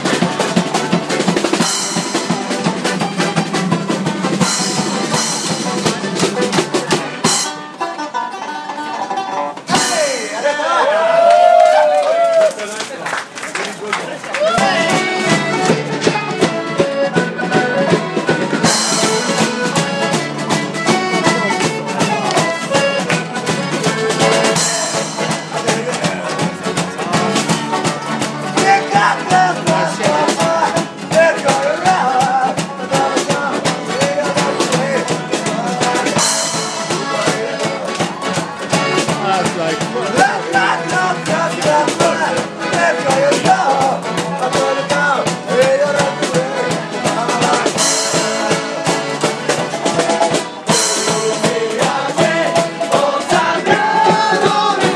Kichijoji street jam session - very charming!